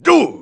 Line of Klump in Donkey Kong: Barrel Blast.